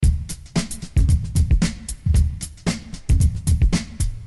Бит играет стремительно